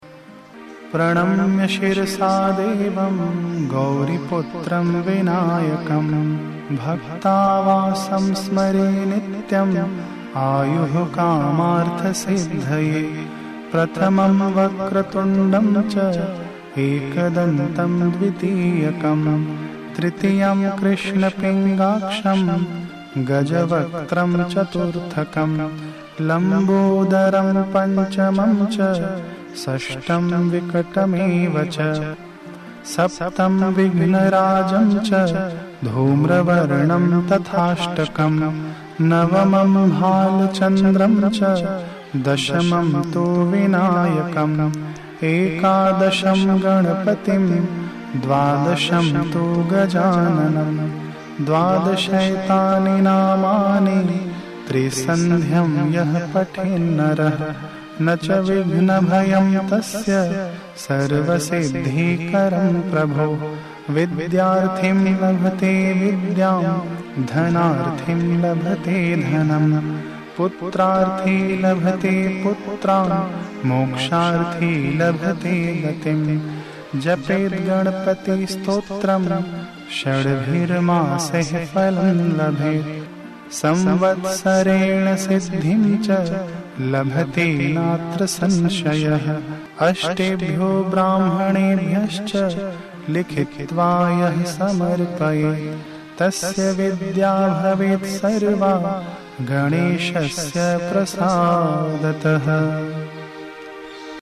Shri Sankat Nashan Ganesh Stotram in Sanskrit